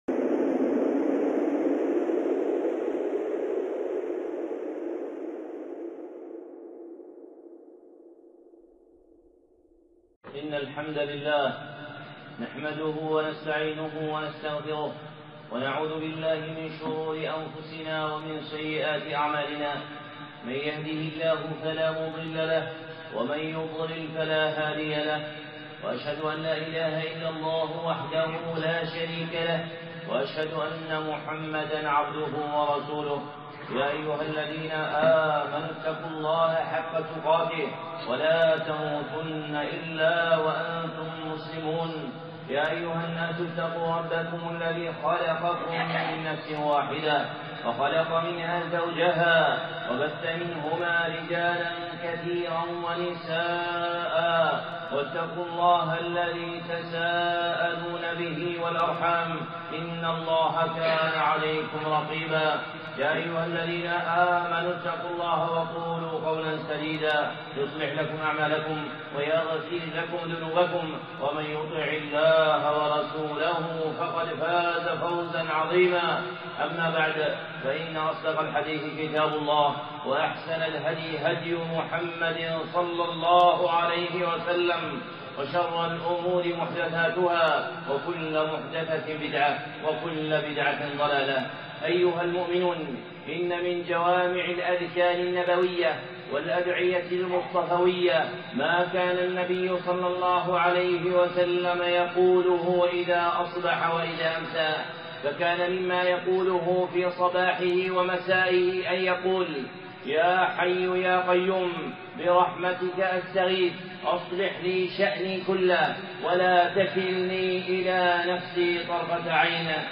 خطبة (يا حي يا قيوم برحمتك أستغيث)